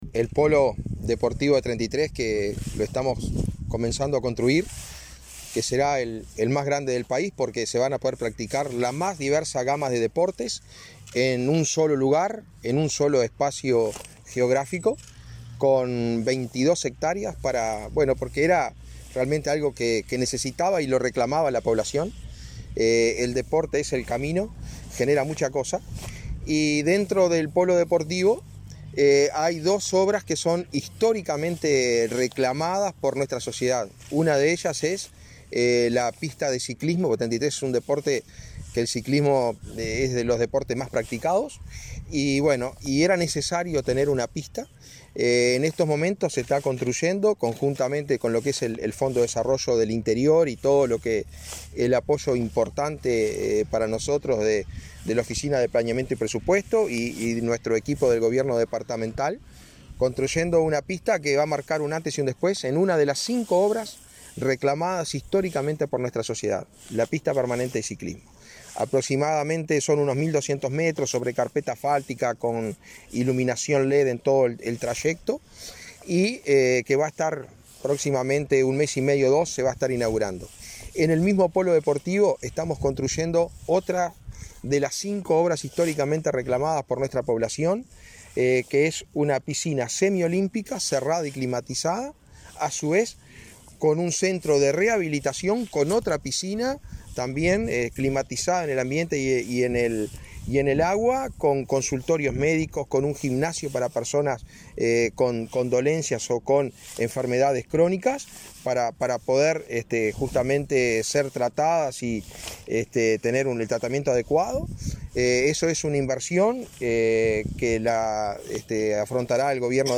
Declaraciones del intendente de Treinta y Tres